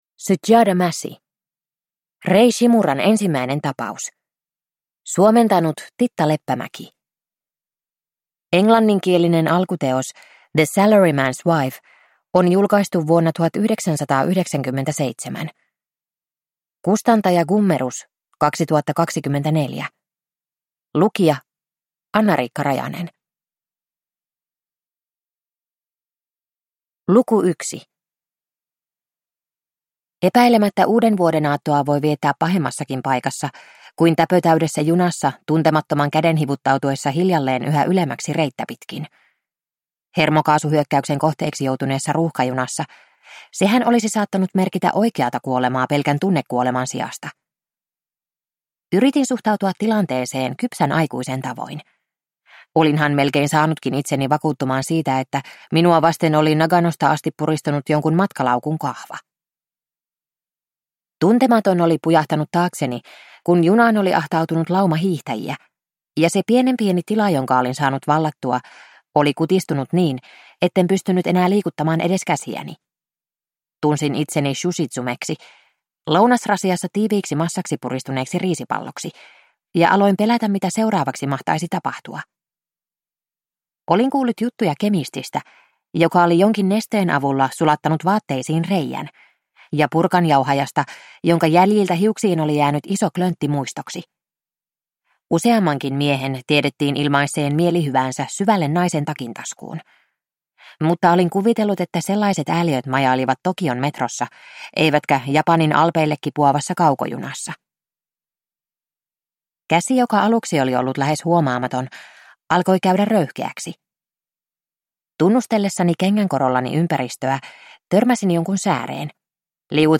Rei Shimuran ensimmäinen tapaus (ljudbok) av Sujata Massey